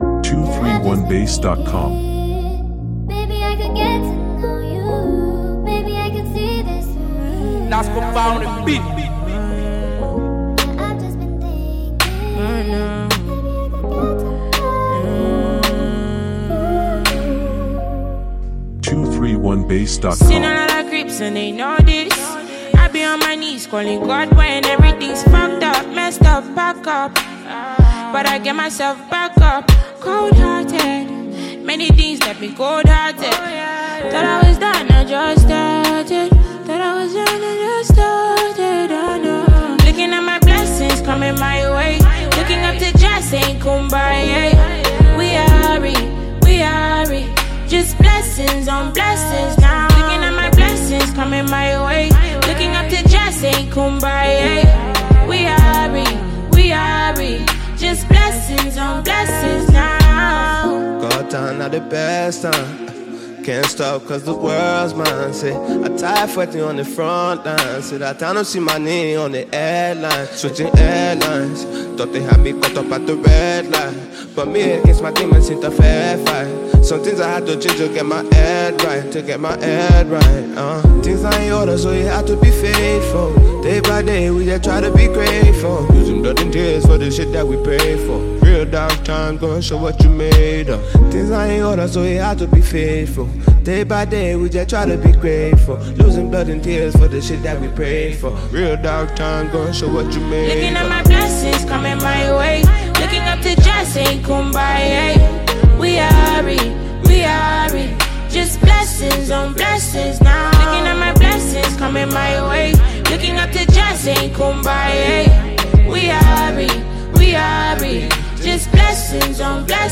smooth flow